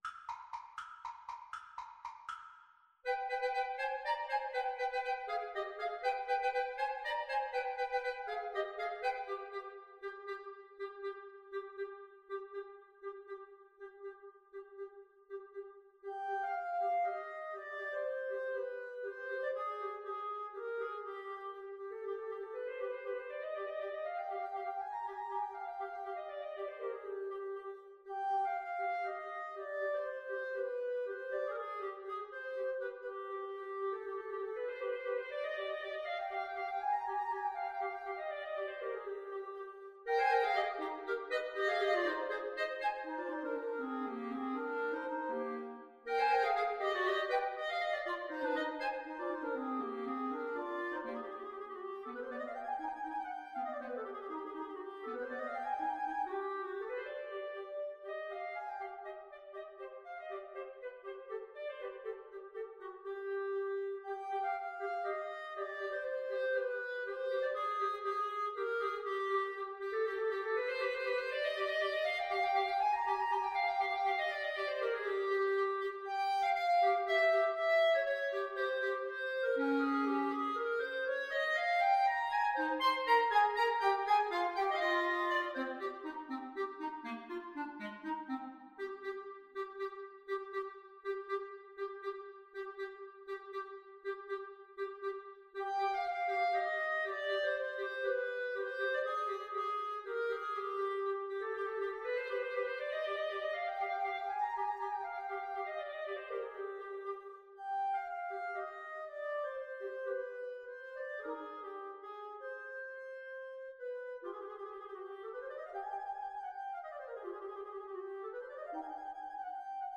Allegro vivo (.=80) (View more music marked Allegro)
Clarinet Trio  (View more Advanced Clarinet Trio Music)
Classical (View more Classical Clarinet Trio Music)